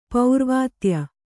♪ paurvātya